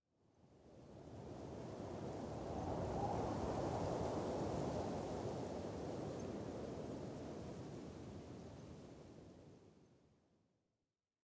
wind3.ogg